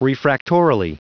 Prononciation du mot refractorily en anglais (fichier audio)
Prononciation du mot : refractorily